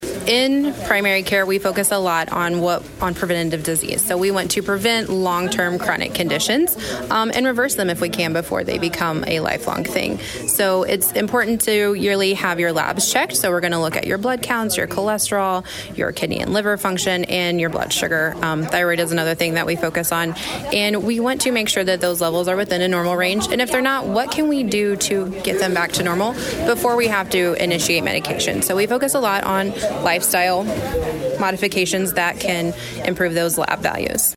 Desloge, Mo. (KFMO) - During Tuesday’s Desloge Chamber of Commerce luncheon, health professionals highlighted the importance of preventive care and community growth in Desloge.